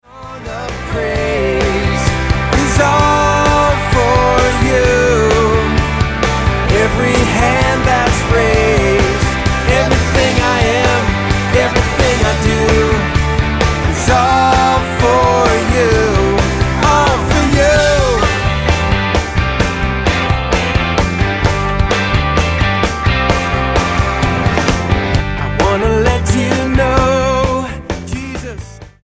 STYLE: Pop